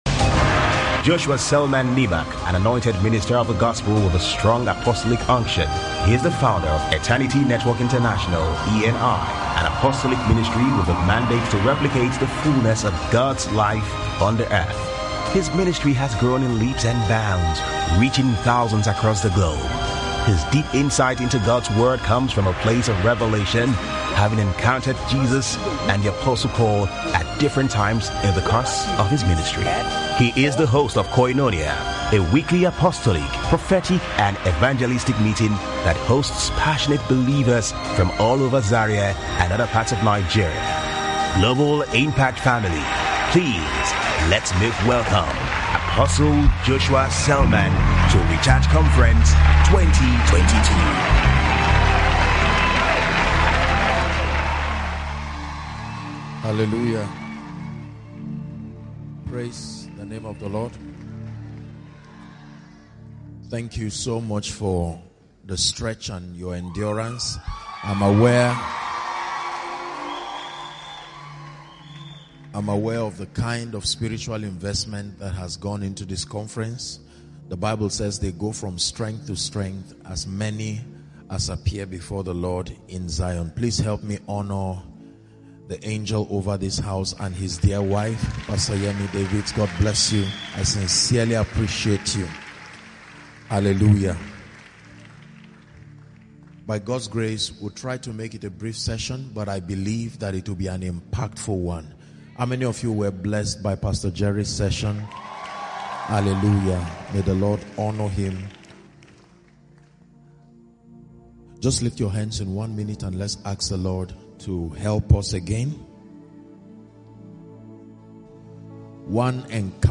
Walking in God’s Favour (Recharge Conference 2022